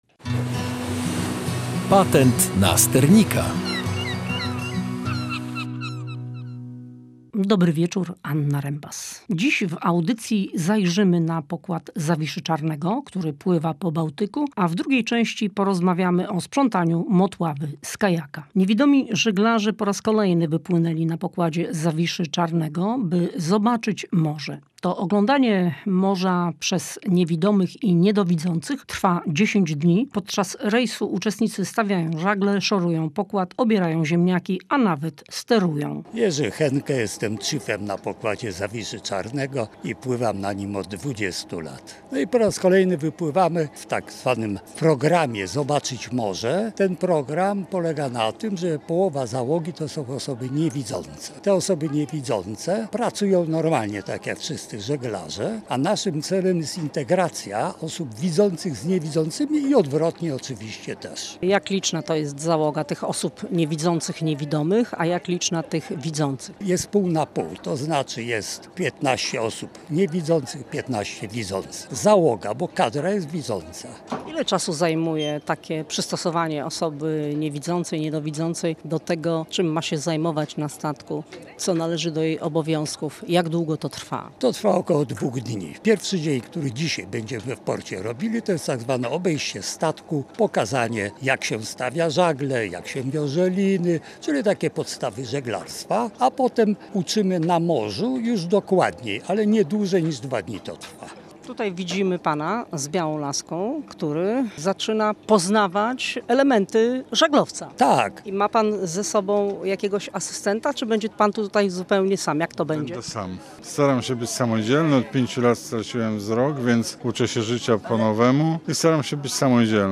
W audycji „Patent na sternika” zajrzeliśmy na pokład żaglowca Zawisza Czarny.
W drugiej części audycji zajrzeliśmy na Motławę.